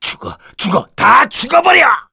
voices / heroes / kr
Dimael-Vox_Skill4_kr.wav